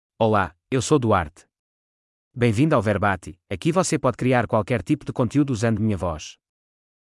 Duarte — Male Portuguese (Portugal) AI Voice | TTS, Voice Cloning & Video | Verbatik AI
Duarte is a male AI voice for Portuguese (Portugal).
Voice sample
Male
Duarte delivers clear pronunciation with authentic Portugal Portuguese intonation, making your content sound professionally produced.